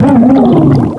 pokeemerald / sound / direct_sound_samples / cries / jellicent.aif
jellicent.aif